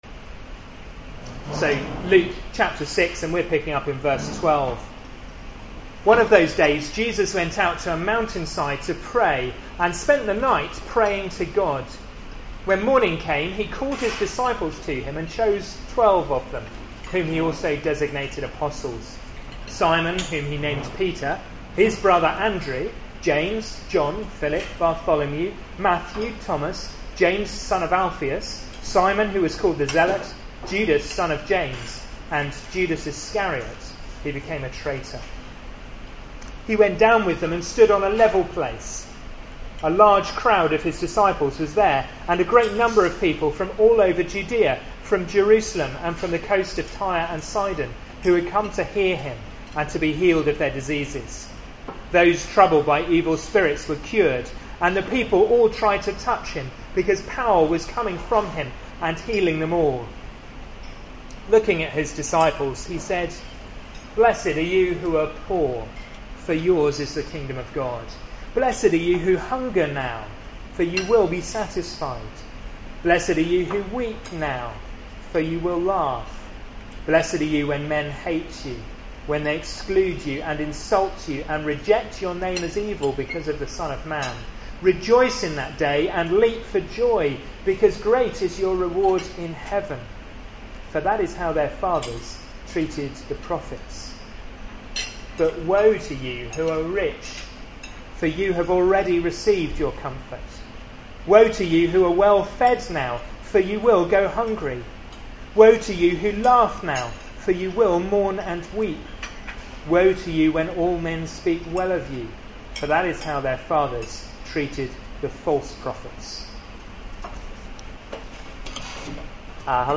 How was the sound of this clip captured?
given at a Wednesday meeting